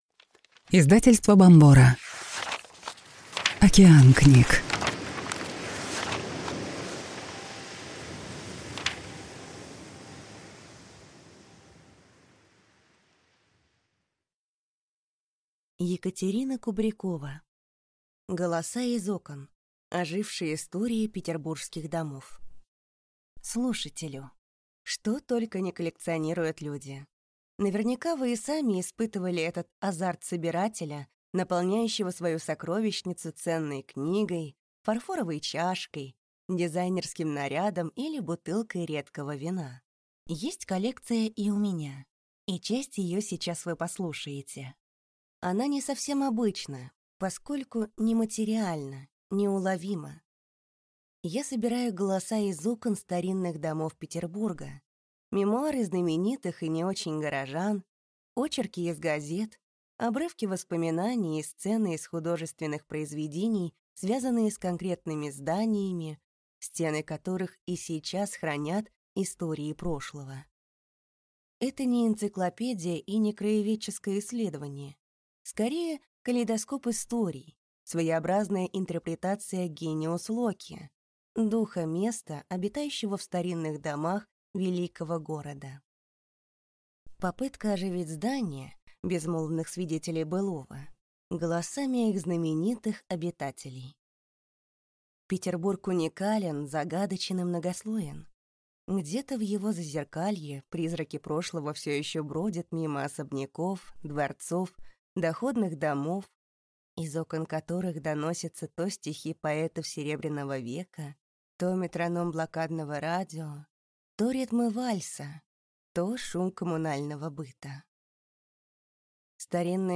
Аудиокнига Голоса из окон: ожившие истории Петербургских домов | Библиотека аудиокниг